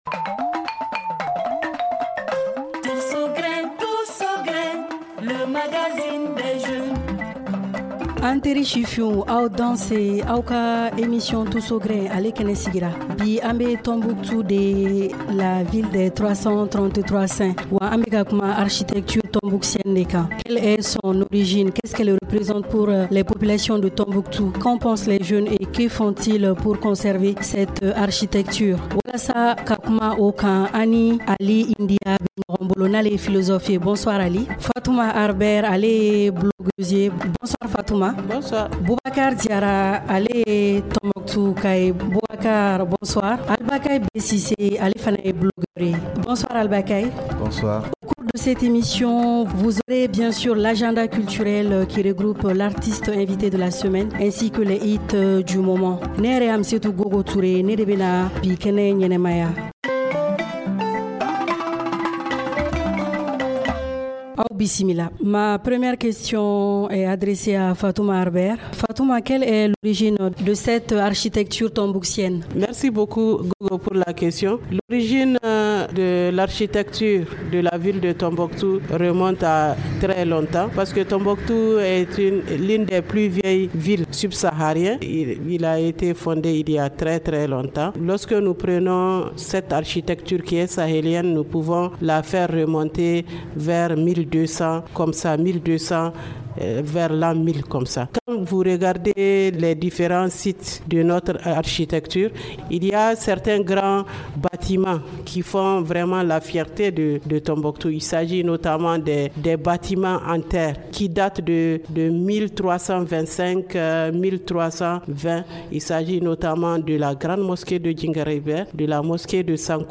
Que pensent les jeunes de cette architecture ? Que font-ils pour la conserver ? Le « Tous au grin » de cette semaine pose le débat et c’est délocalisé à Tombouctou avec les invités: